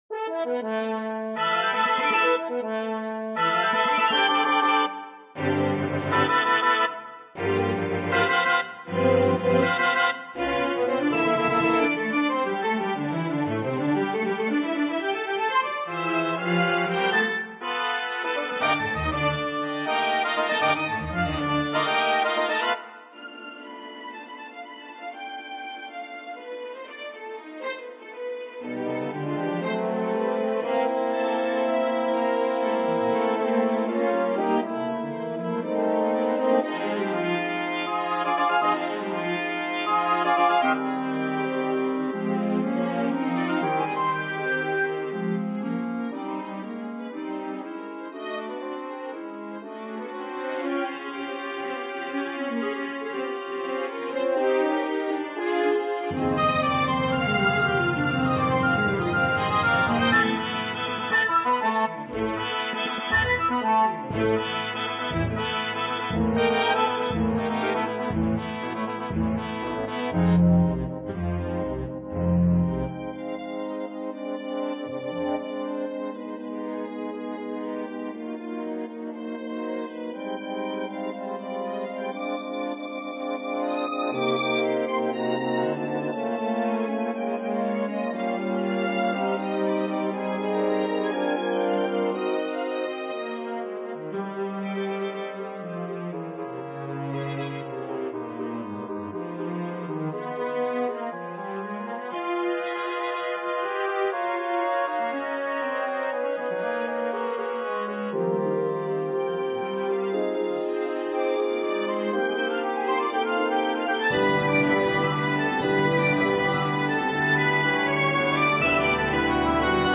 Orchestration
Strings (Violin 1, Violin 2, Viola, Cello, Double Bass)